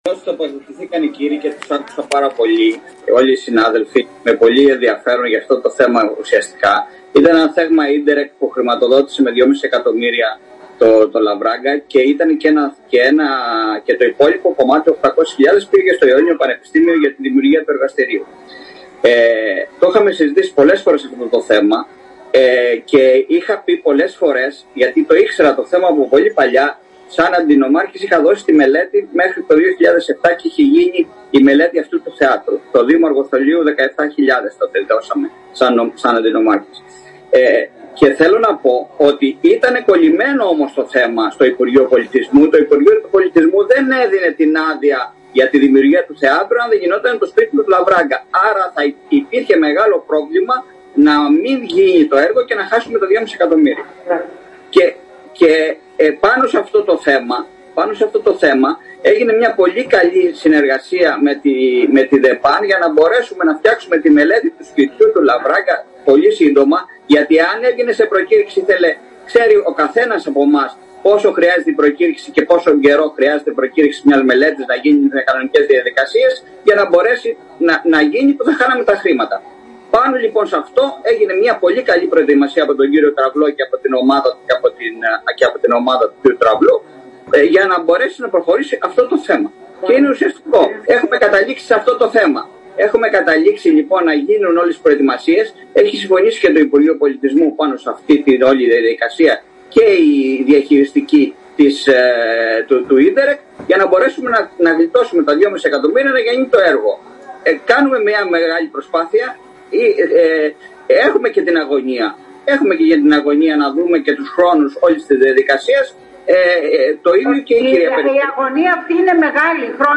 Στη συνεδρίαση του Περιφερειακού Συμβουλίου την περασμένη Δευτέρα συζητήθηκε και το θέμα του Θέατρου Λαυράγκα στα Ραζάτα.
Έλαβε το λόγο ο κ. Σ. Κουρής ο οποίος έδωσε νέες …..προοπτικές στην συζήτηση γνωρίζοντας κι αυτός το θέμα καλά μιας και είχε θητεύσει αντιπεριφερειάρχης επί θητείας Σπύρου Σπύρου.